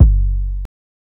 ILLMD020_KICK_SOB.wav